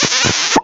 Play, download and share K i ss original sound button!!!!
kiss_VGvTAfo.mp3